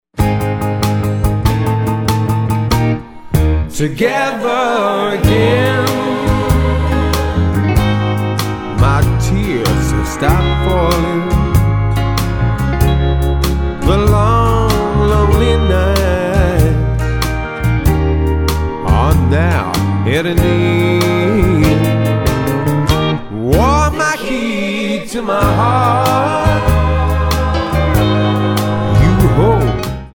Tonart:Ab Multifile (kein Sofortdownload.
Die besten Playbacks Instrumentals und Karaoke Versionen .